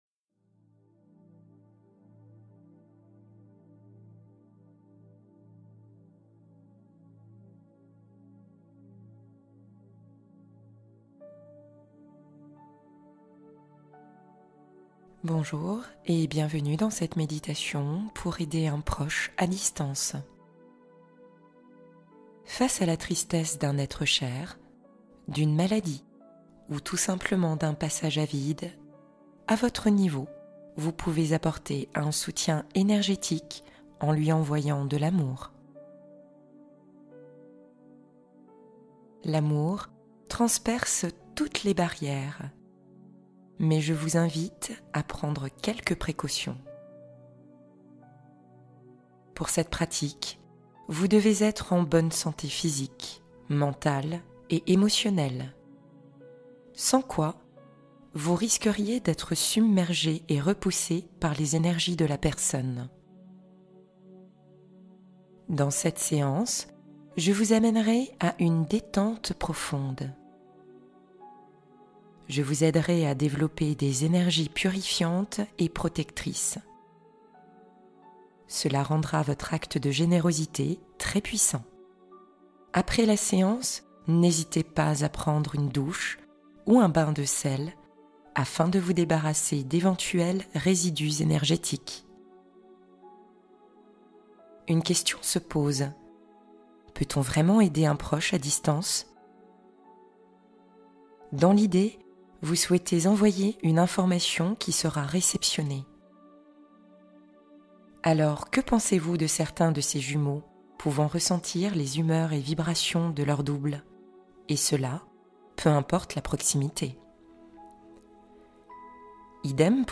Se retrouver pleinement : relaxation guidée et affirmations réparatrices